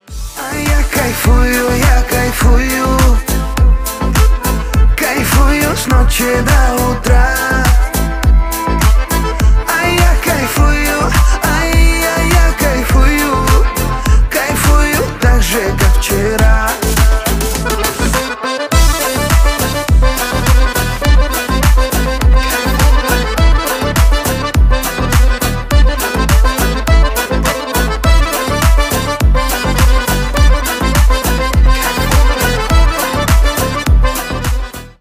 веселые , поп